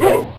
jump.ogg